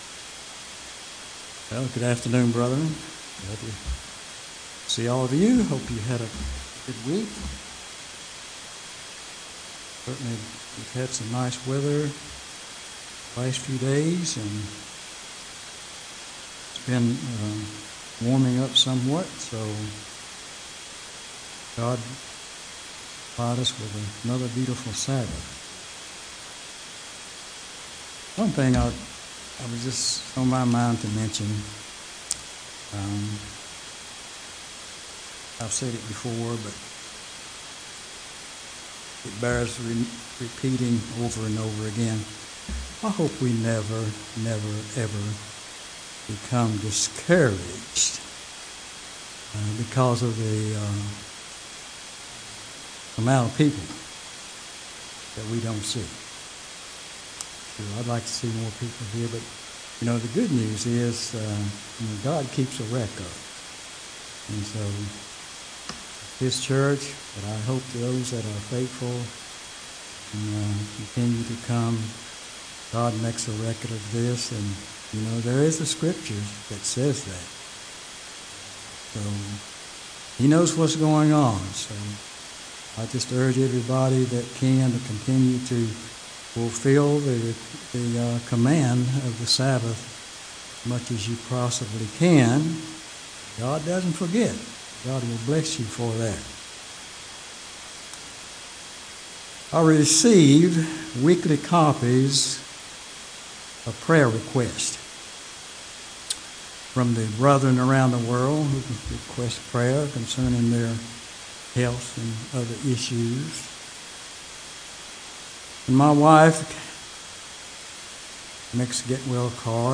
Sermons
Given in Greensboro, NC